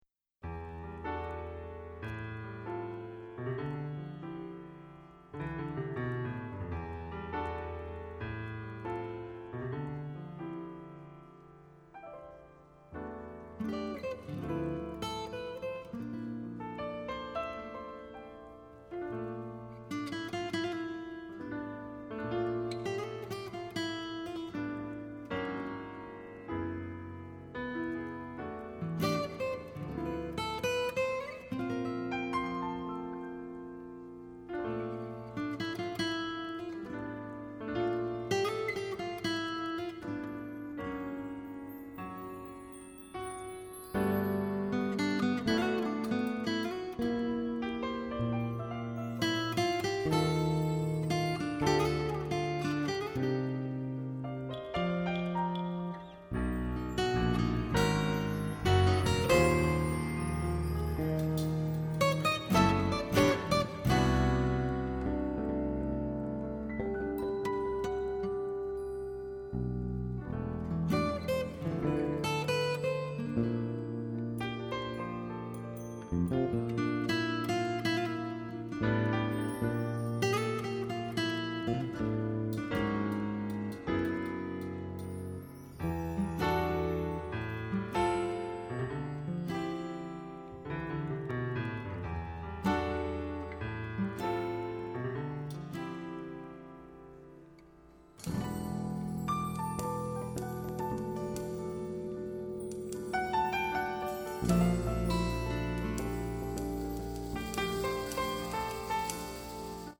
-   爵士及藍調 (298)
★ 精妙吉他演奏，震撼鼓聲與鋼琴，豐富聆賞效果！